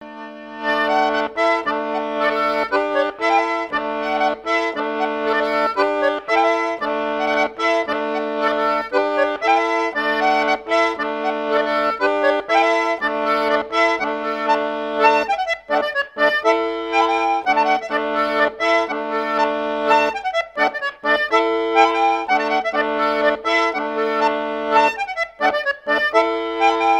danse : ronde : grand'danse
circonstance : maritimes
Pièce musicale éditée